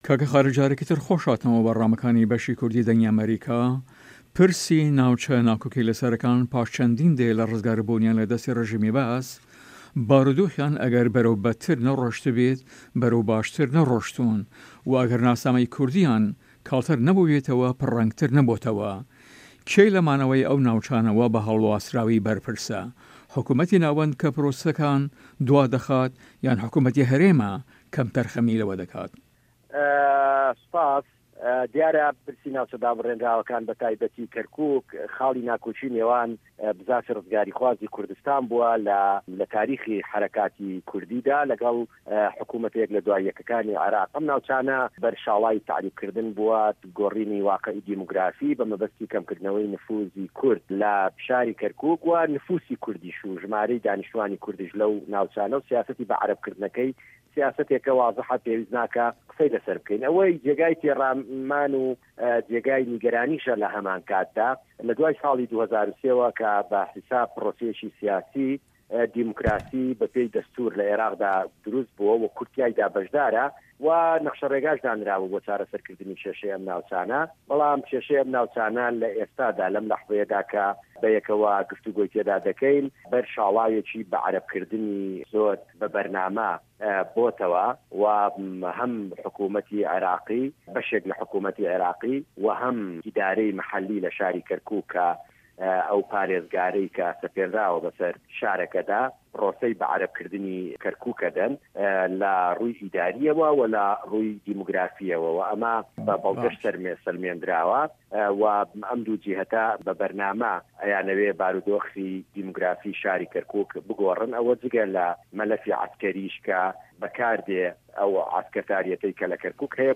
Interview with Khalid Shwani